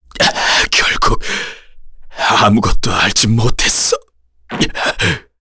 Chase-Vox_Dead_kr.wav